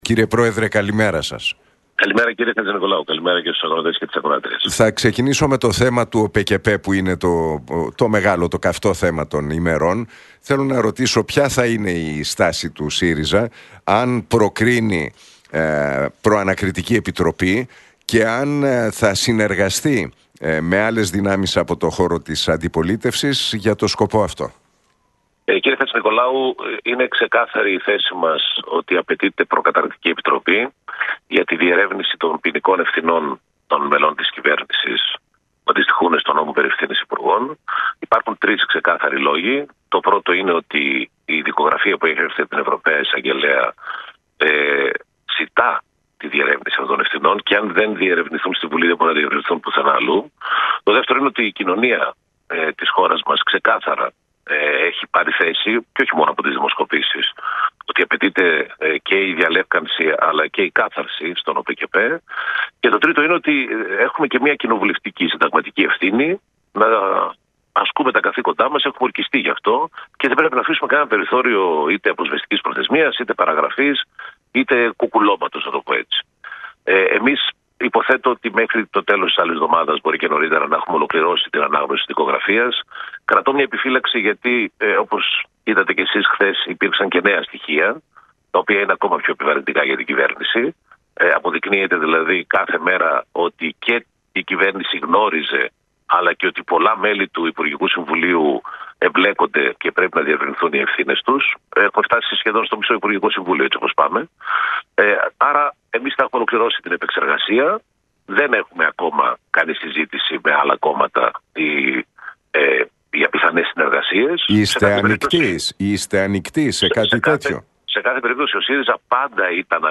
Για το σκάνδαλο με τις παράνομες επιδοτήσεις στον ΟΠΕΚΕΠΕ μίλησε ο πρόεδρος του ΣΥΡΙΖΑ – ΠΣ, Σωκράτης Φάμελλος στον Νίκο Χατζηνικολάου από την συχνότητα του Realfm 97,8.